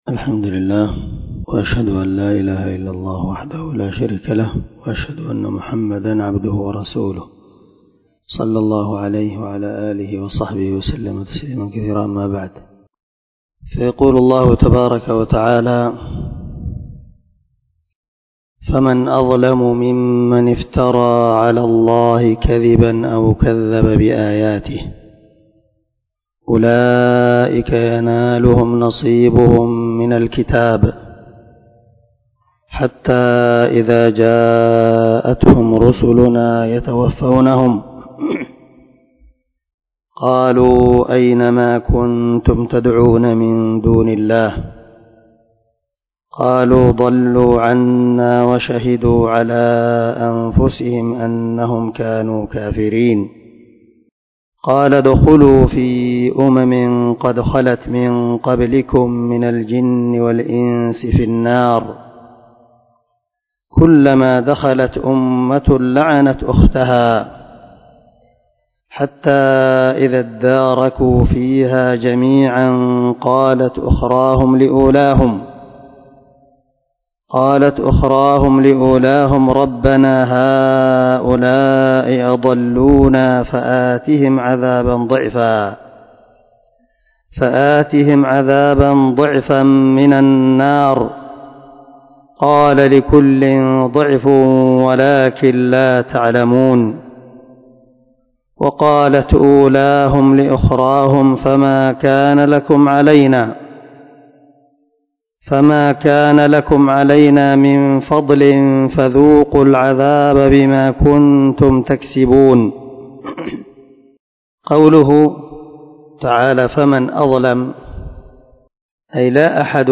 460الدرس 12 تفسير آية ( 37 – 39 ) من سورة الأعراف من تفسير القران الكريم مع قراءة لتفسير السعدي